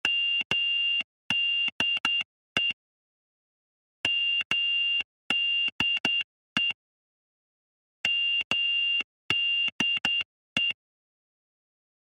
Para la segunda obra propuso una traducción de la clave Morse –originalmente transmitida por radio– a una melodía polifónica para el celular; gesto que da cuenta no solo de la conciencia del artista sobre las posibilidades de renovación expresiva de su propio trabajo, sino, además, de su capacidad para integrar a las estrategias del arte contemporáneo medios y lenguajes que normalmente operan con finalidades comunicativas prácticas.